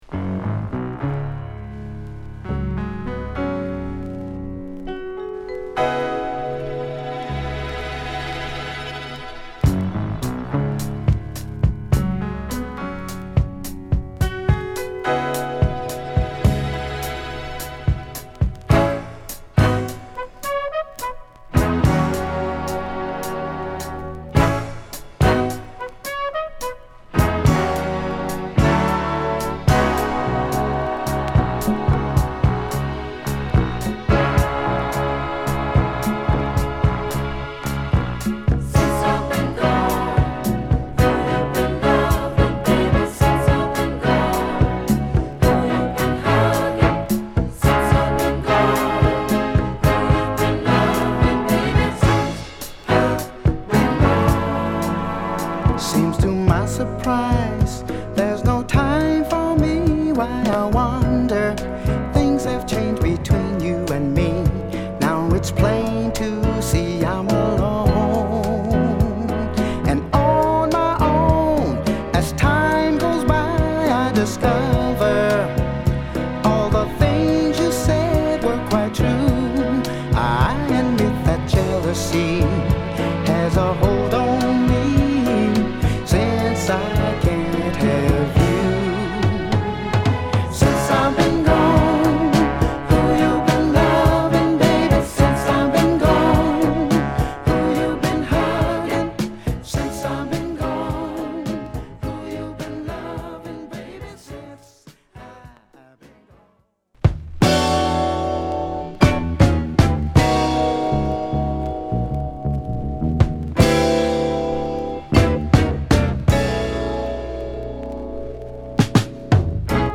純度高いフィリー・ソウルアルバムに